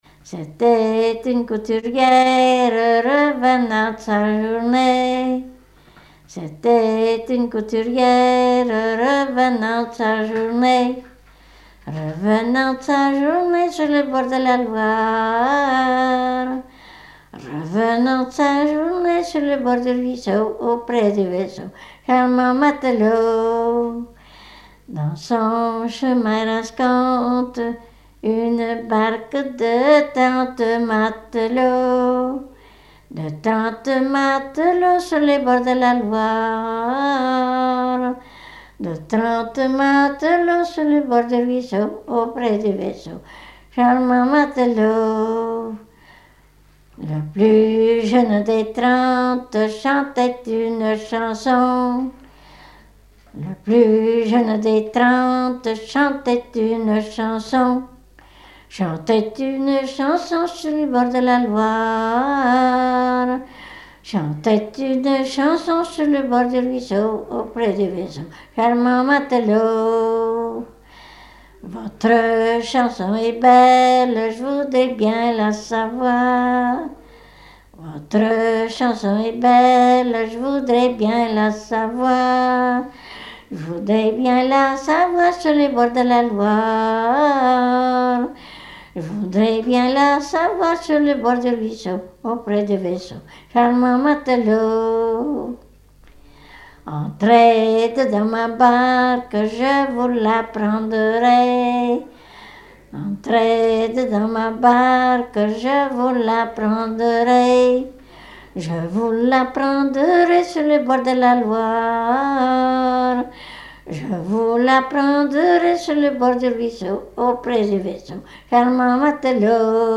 Genre laisse
Chansons traditionnelles et populaires
Pièce musicale inédite